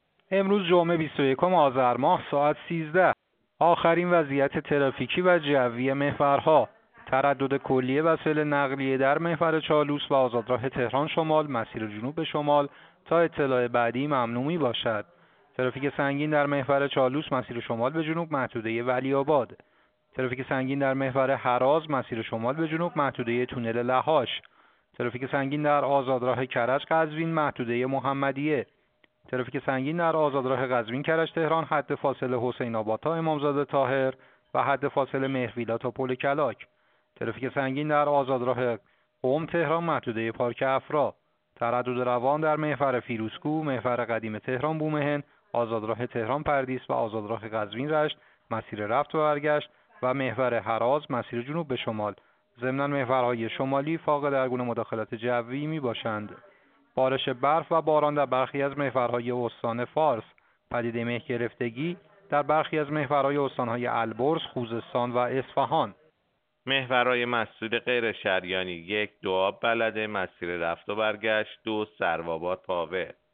گزارش رادیو اینترنتی از آخرین وضعیت ترافیکی جاده‌ها ساعت ۱۳ بیست‌ویکم آذر؛